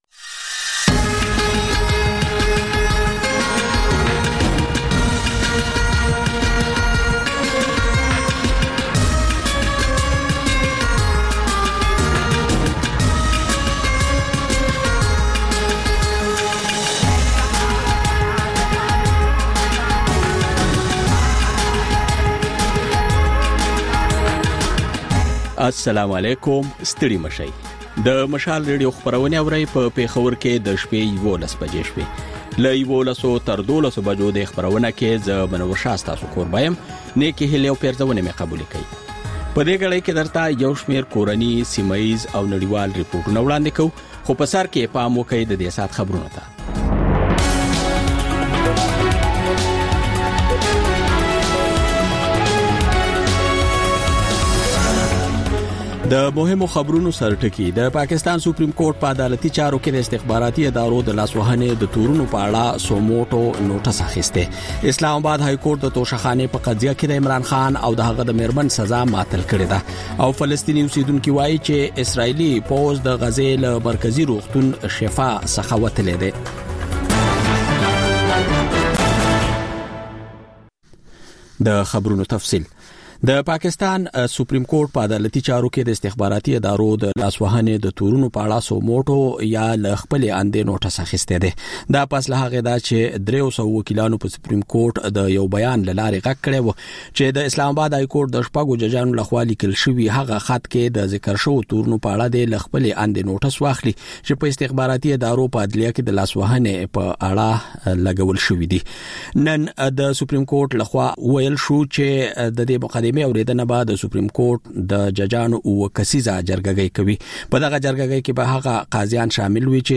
دلته د مشال راډیو د ۱۴ ساعته خپرونو دویمه او وروستۍ خبري ګړۍ تکرار اورئ. په دې خپرونه کې تر خبرونو وروسته بېلا بېل سیمه ییز او نړیوال رپورټونه، شننې، مرکې، رسنیو ته کتنې، کلتوري او ټولنیز رپورټونه خپرېږي.